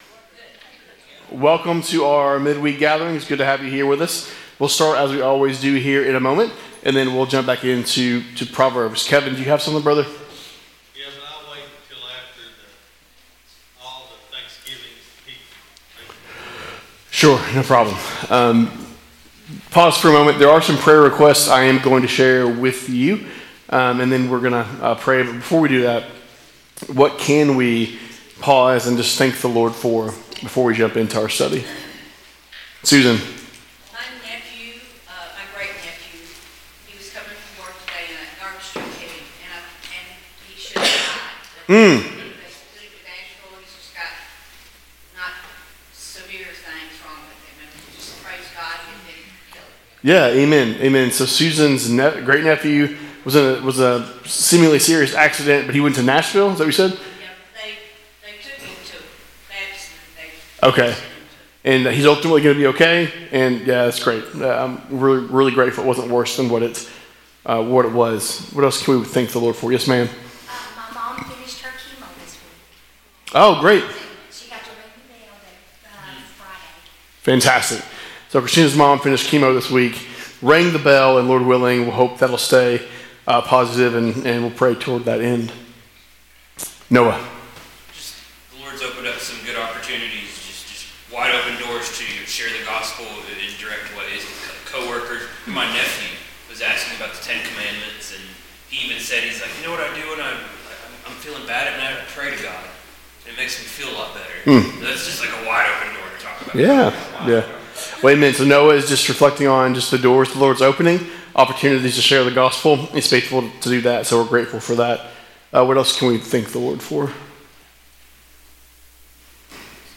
Wednesday Evening Bible Studies - 6:30pm